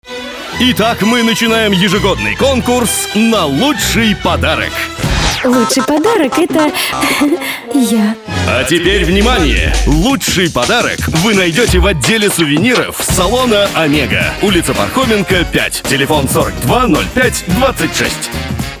Игровой ролик (2 голоса, 2-3 подложки, игровая ситуация, спец эффекты)